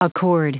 Prononciation du mot accord en anglais (fichier audio)
Prononciation du mot : accord